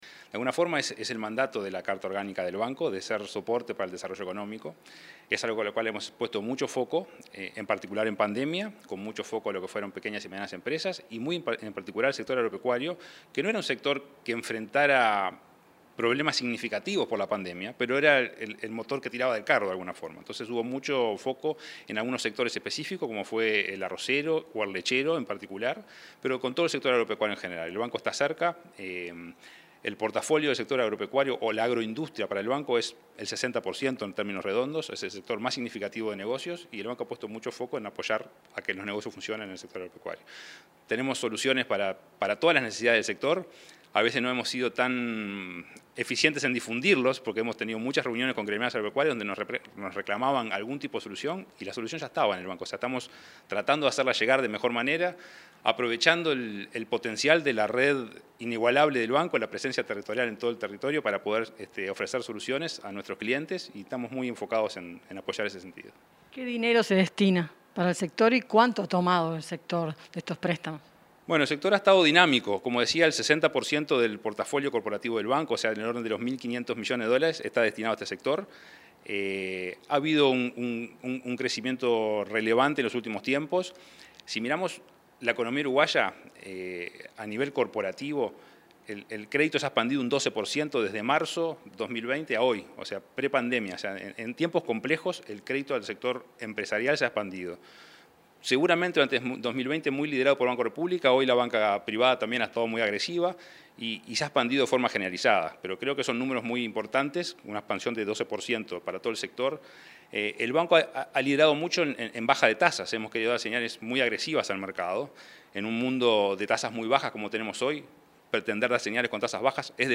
Entrevista al presidente del BROU, Salvador Ferrer